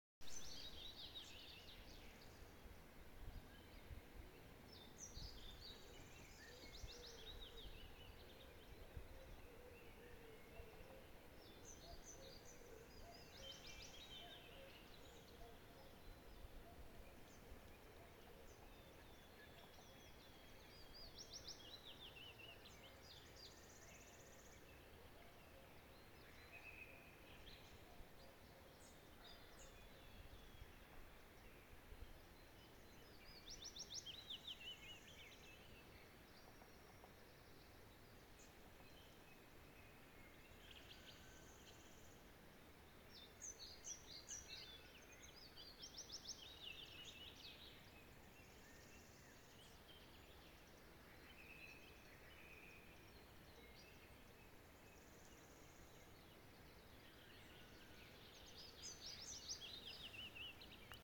Putns (nenoteikts), Aves sp.
СтатусСлышен голос, крики